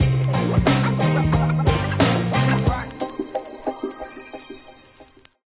brawl_underwater_load_01.ogg